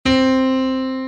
NoteC.mp3